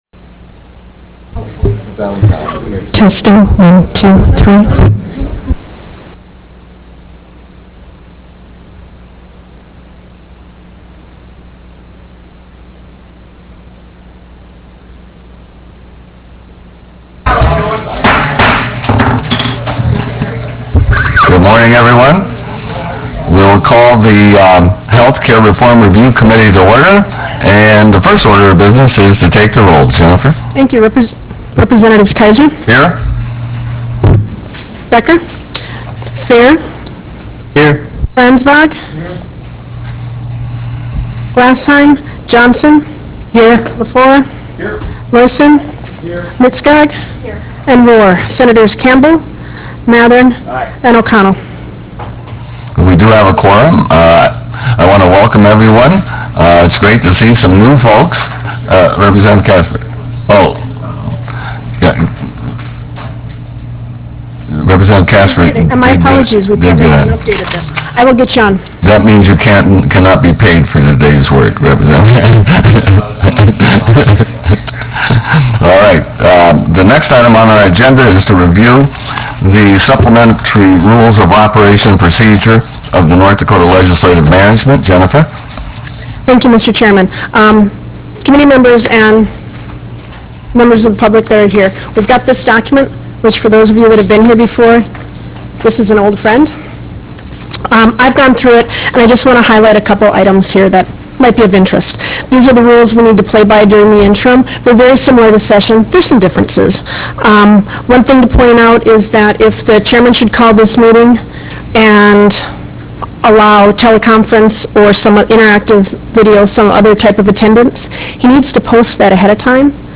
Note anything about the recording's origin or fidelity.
Roughrider Room State Capitol Bismarck, ND United States